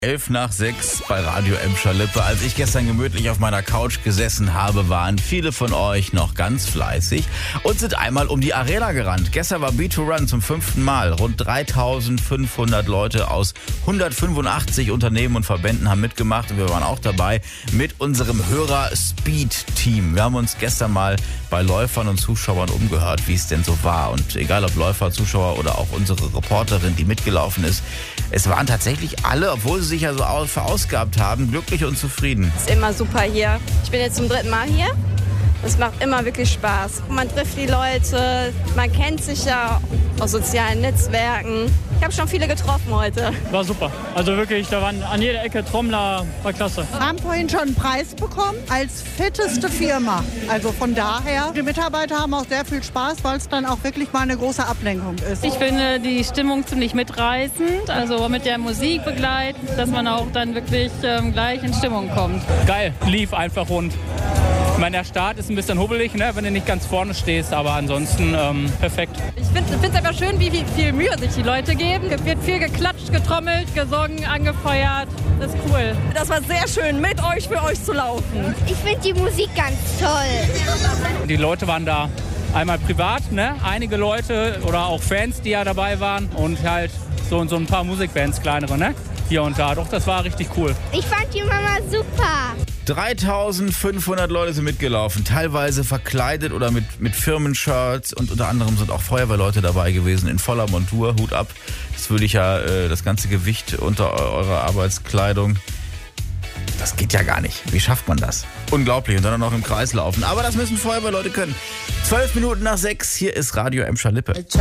Wir haben uns gestern mal bei Läufern und Zuschauern umgehört, wie es denn so war.